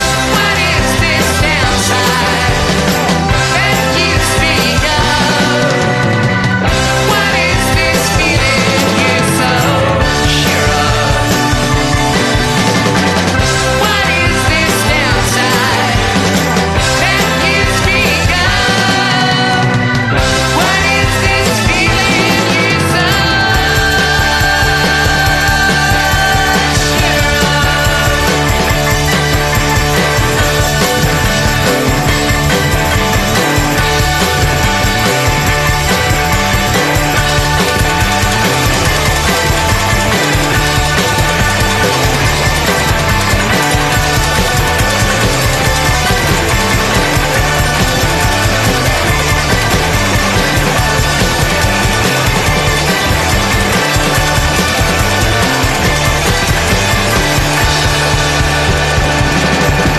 a common Chicago accent